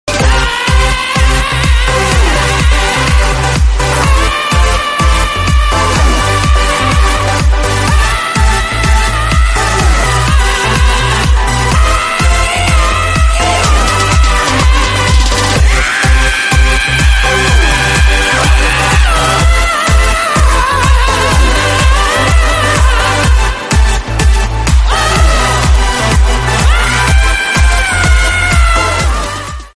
Jimmy Barnes Yelling Screaming Cowboy